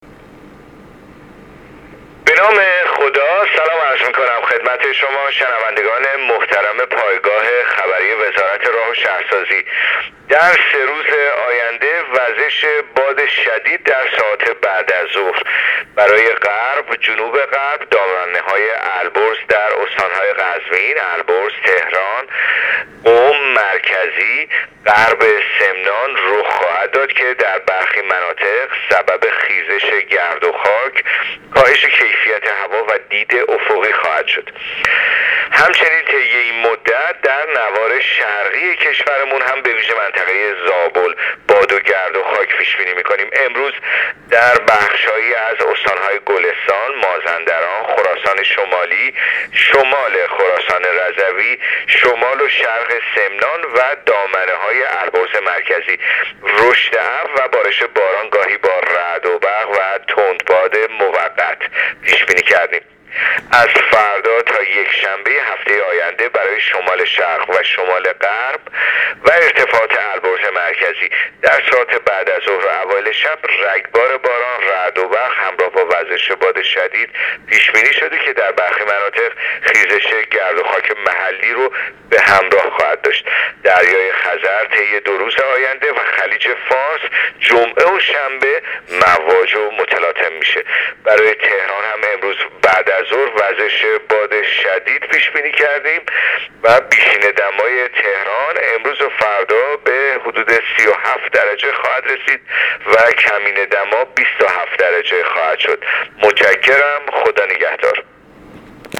کارشناس سازمان هواشناسی در گفت‌وگو با رادیو اینترنتی پایگاه خبری وزارت راه‌ و شهرسازی، آخرین وضعیت آب‌وهوای کشور را تشریح کرد.
گزارش رادیو اینترنتی پایگاه خبری از آخرین وضعیت آب‌وهوای هجدهم خرداد؛